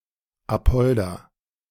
Apolda (pronunciación en alemán:
/aˈpɔlda/) es una ciudad alemana del estado de Turingia.